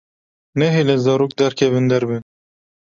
Pronunciado como (IPA)
/zɑːˈroːk/